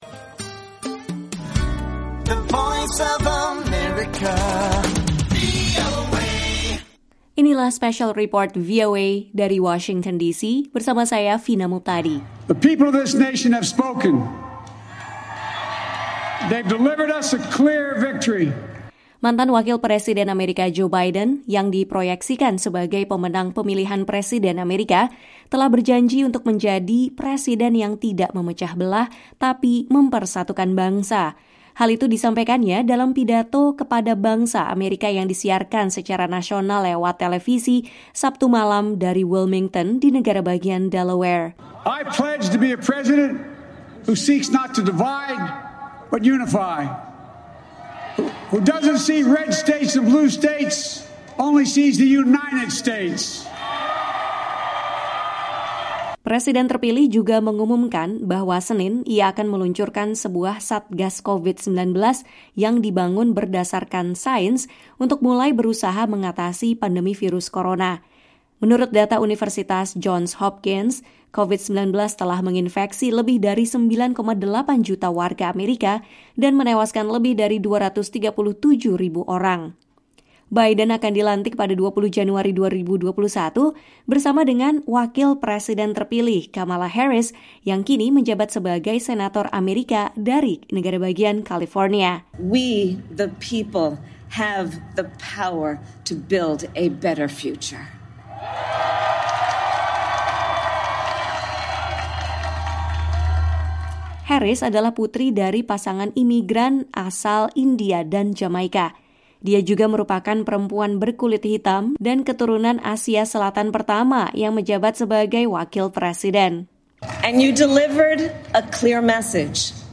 Hal itu disampaikannya dalam pidato kepada bangsa pada Sabtu malam (7/11) dari Wilmington, negara bagian Delaware.
"Ketika demokrasi kita berada dalam surat suara dalam pemilu ini, dengan jiwa raga Amerika dipertaruhkan dan disaksikan dunia, Anda membawa hari baru bagi Amerika," katanya kepada massa yang berkumpul di luar ruangan sambil menjaga jarak.